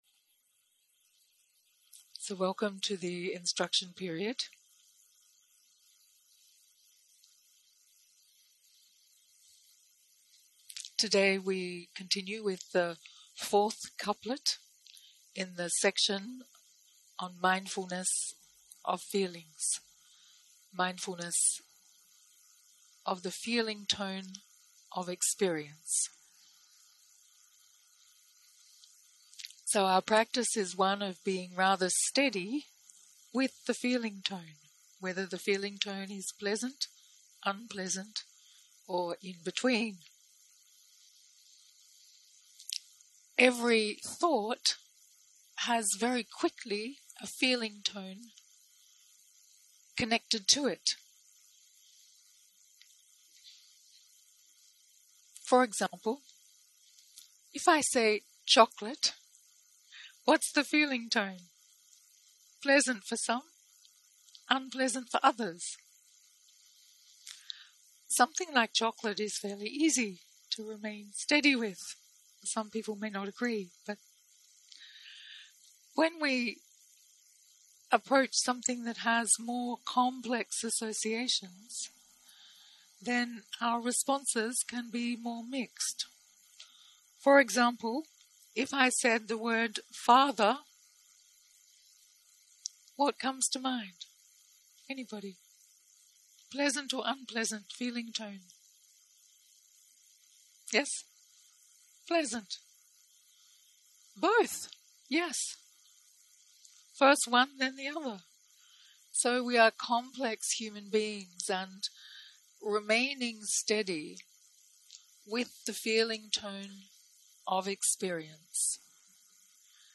יום 3 - הקלטה 4 - בוקר - הנחיות מדיטציה
סוג ההקלטה: שיחת הנחיות למדיטציה